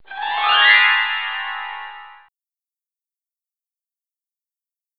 warp.wav